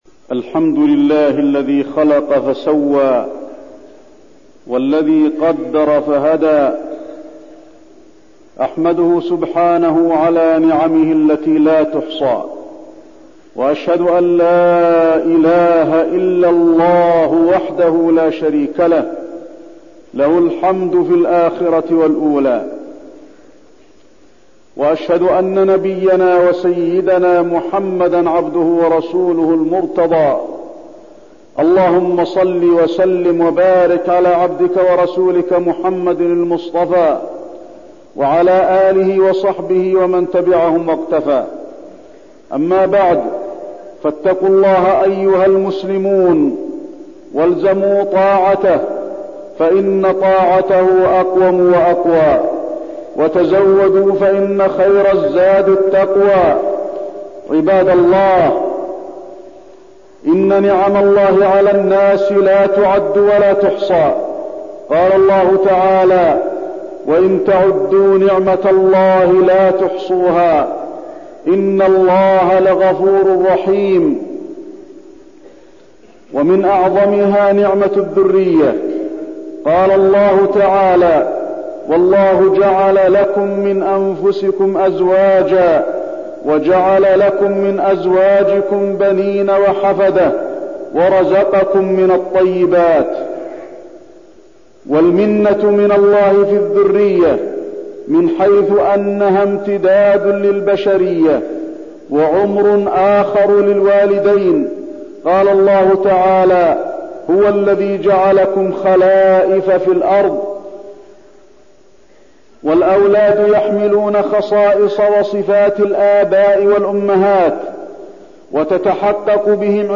تاريخ النشر ١٠ شعبان ١٤٠٩ هـ المكان: المسجد النبوي الشيخ: فضيلة الشيخ د. علي بن عبدالرحمن الحذيفي فضيلة الشيخ د. علي بن عبدالرحمن الحذيفي الأمانة